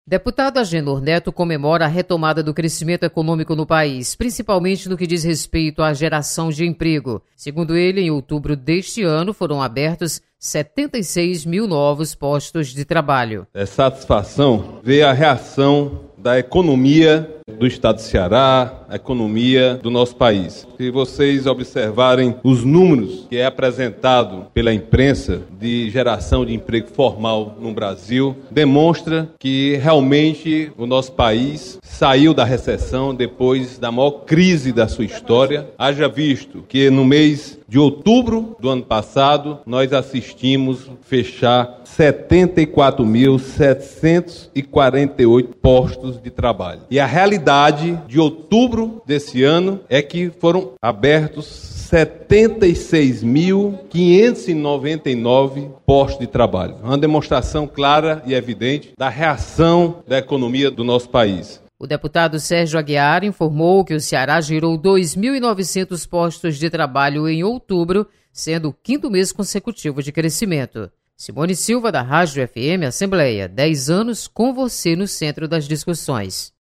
Deputado Agenor Neto comemora crescimento da economia brasileira. Repórter